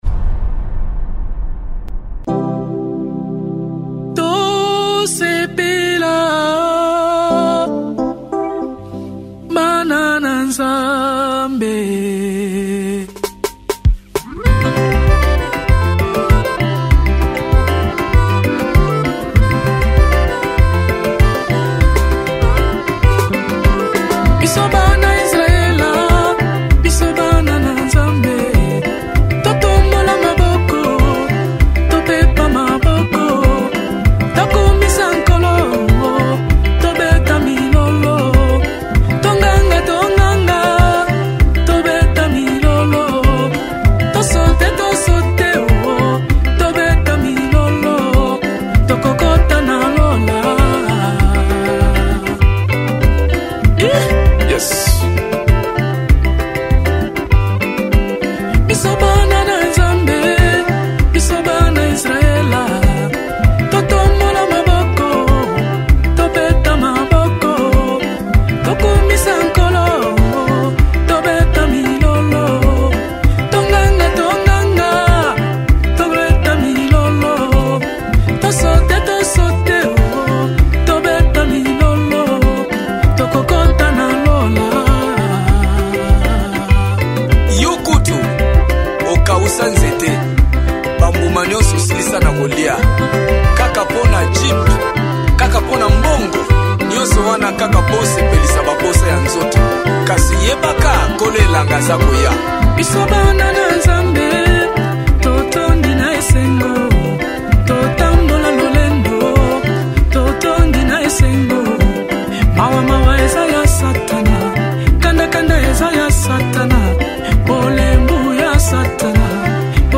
Gospel 2018